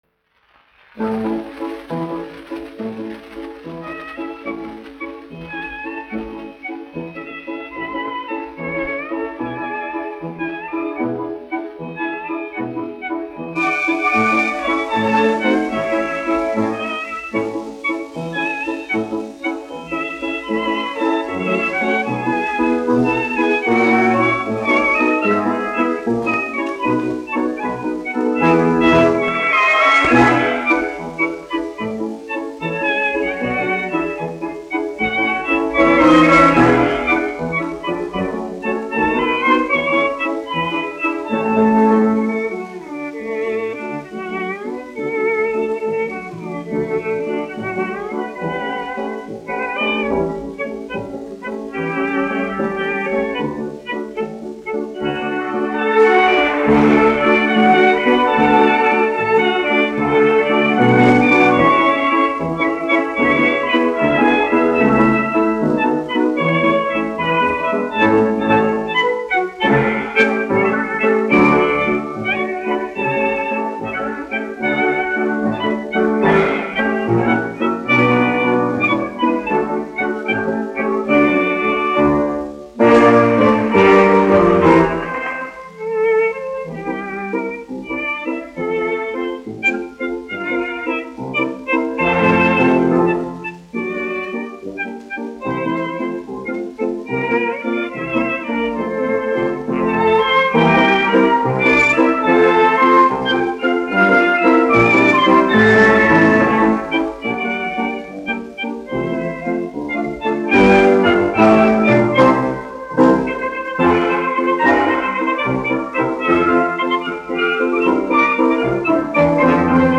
Orķestra mūzika
1 skpl. : analogs, 78 apgr/min, mono ; 25 cm
Latvijas vēsturiskie šellaka skaņuplašu ieraksti (Kolekcija)